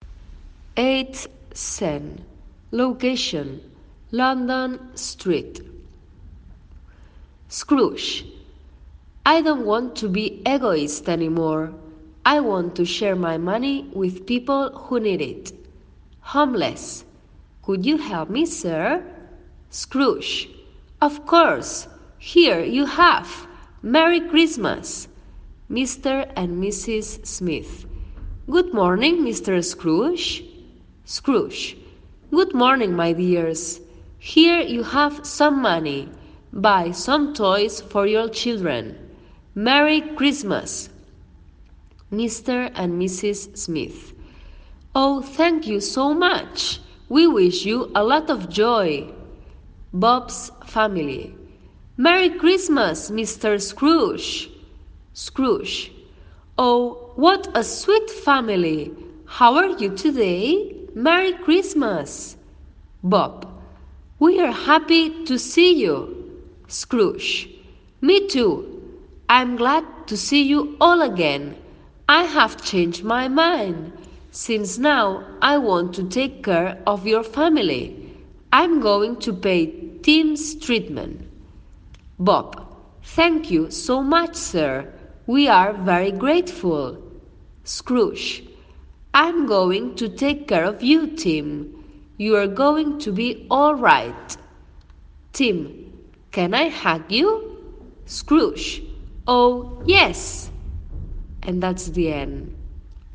There is one very slowly and the other is normal.
Normal speech: